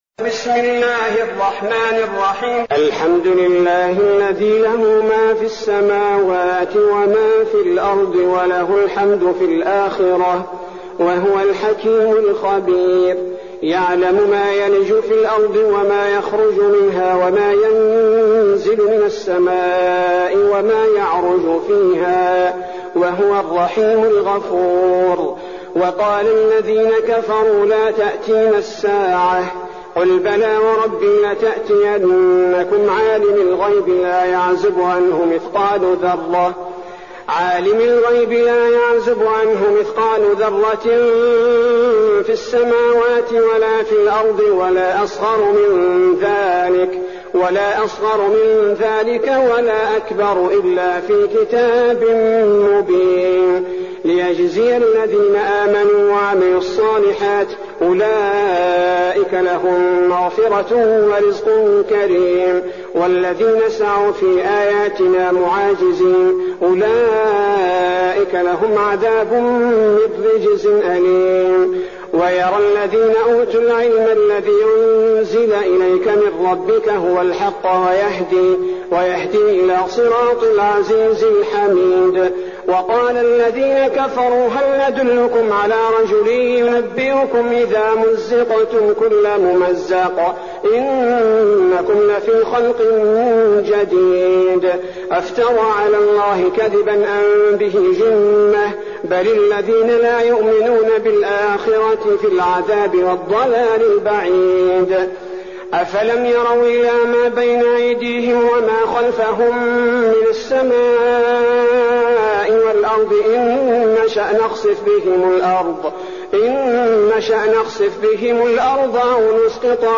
المكان: المسجد النبوي الشيخ: فضيلة الشيخ عبدالباري الثبيتي فضيلة الشيخ عبدالباري الثبيتي سبأ